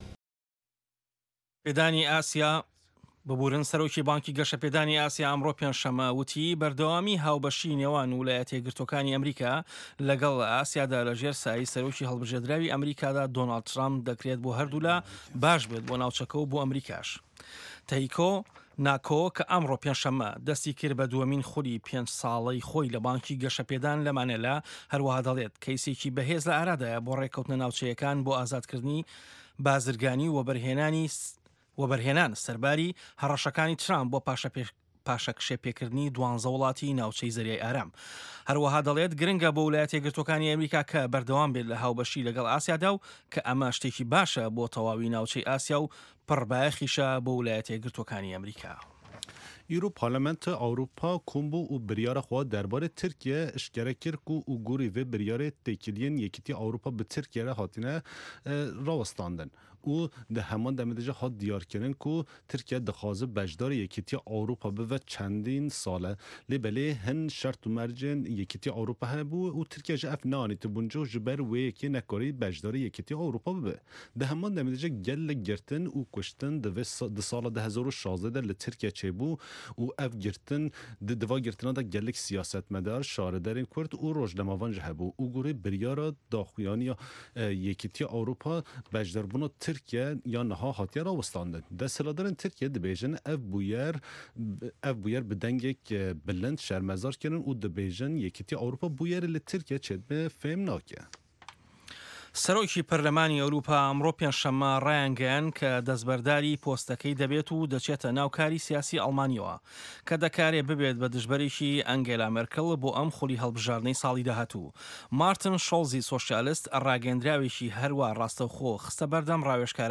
هه‌واڵه‌کان، ڕاپـۆرت، وتووێژ و پاشان سه‌رگوتاری ڕۆژنامه‌ که‌ تیایدا ڕاوبۆچوونی حکومه‌تی ئه‌مه‌ریکا ده‌خرێته‌ ڕوو.